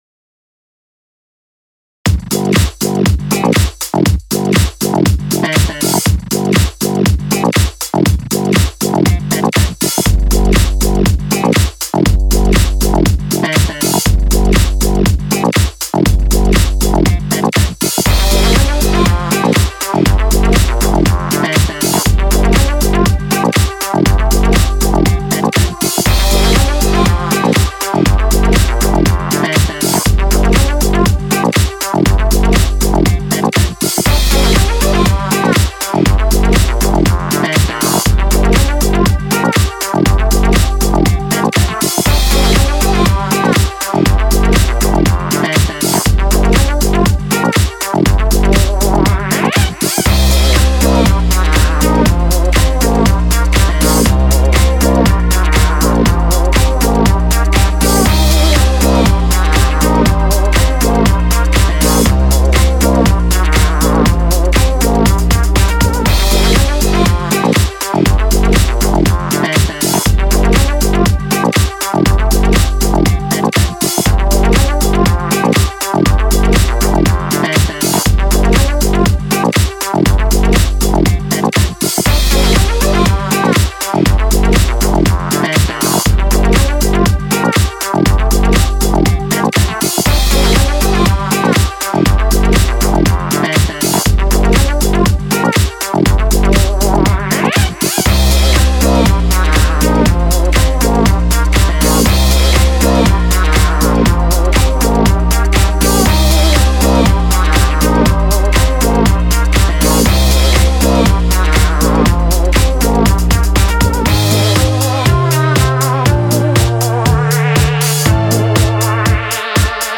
That is some solid funk!
Definitely dripping some funk.  Tight bass.